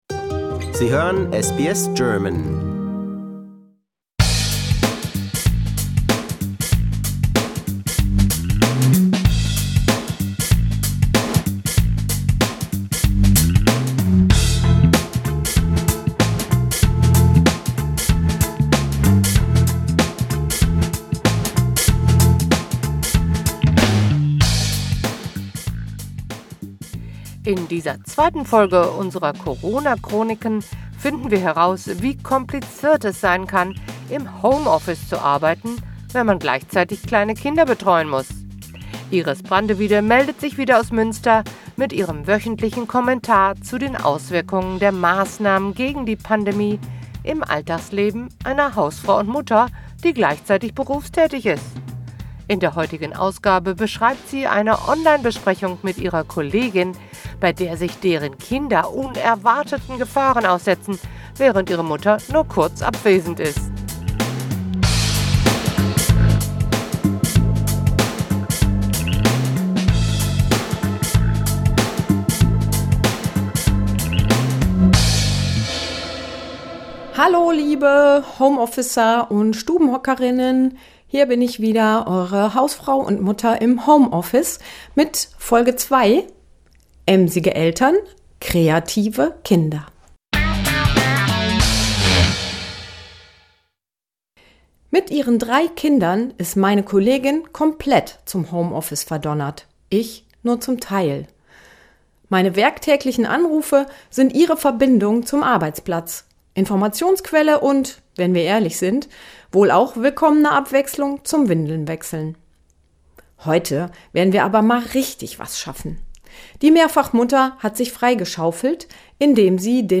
wöchentlichen Kommentar